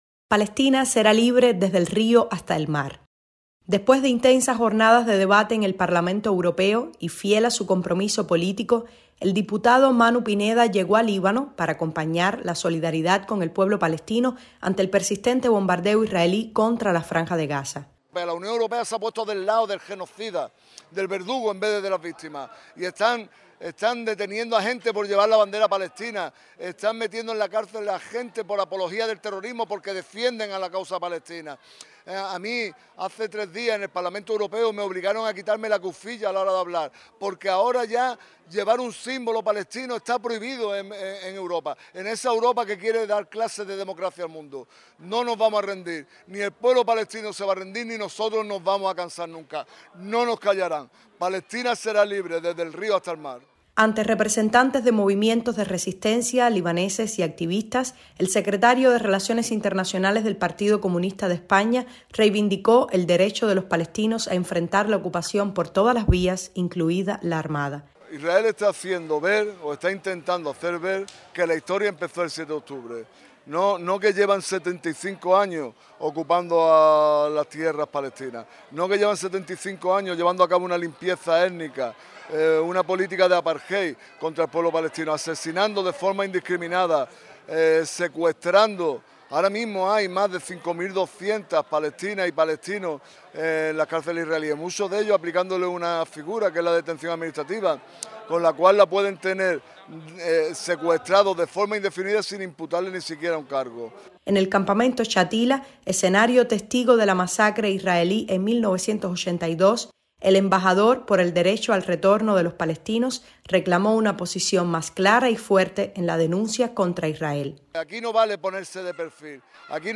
desde Beirut